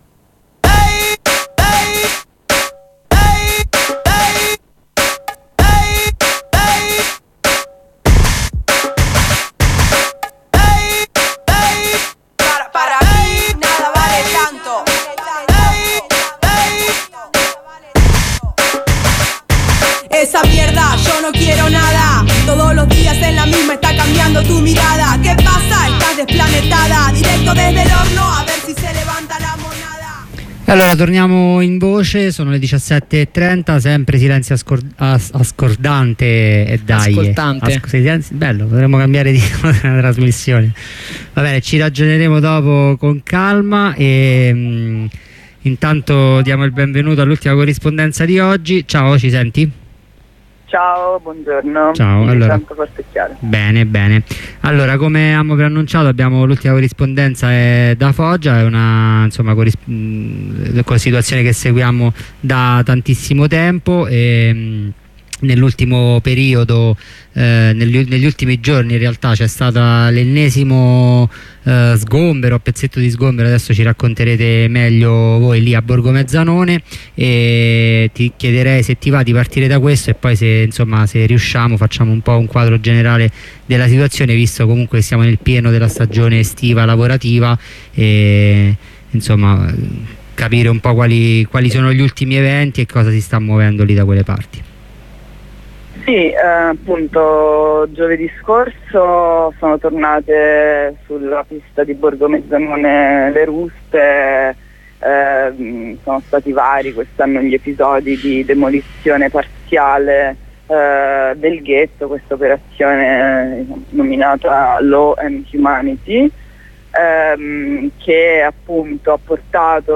Al telefono con una compagna un aggiornamento sulla situazione delle lotte di chi lavora nelle campagne foggiane.